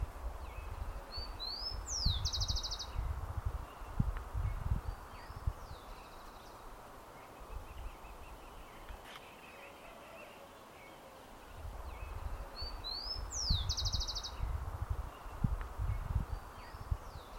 Chingolo (Zonotrichia capensis)
Nombre en inglés: Rufous-collared Sparrow
Localidad o área protegida: Parque Costero del Sur
Condición: Silvestre
Certeza: Observada, Vocalización Grabada
Chingolo.mp3